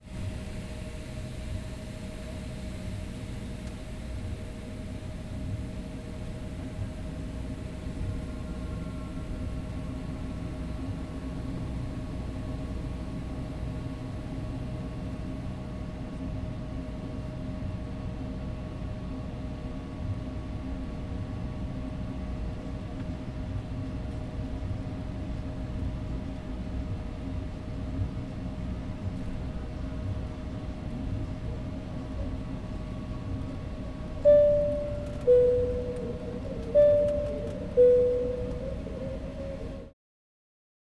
005-Airplane interior, call ding
Airplane Call-button Ding Field-Recording Foley Interior sound effect free sound royalty free Sound Effects